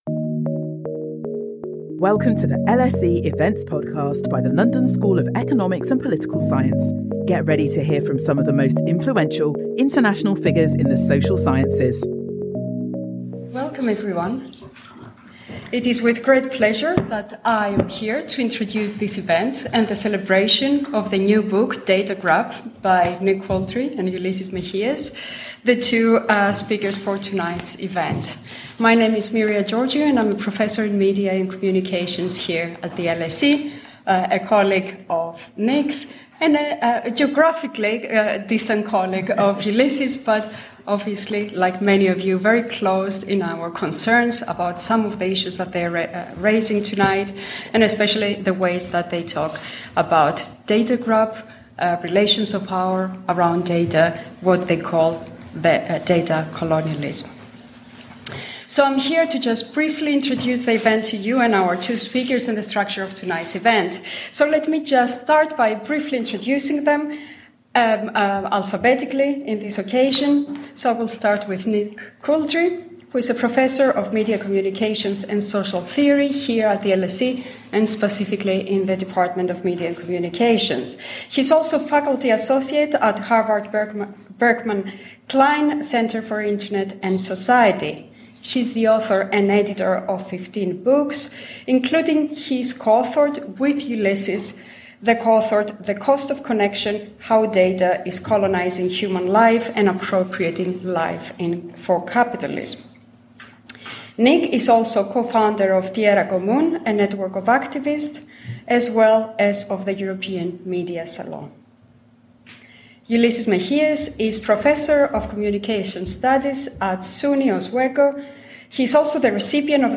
The book launch of Data Grab: The New Colonialism of Big Tech and How to Fight Back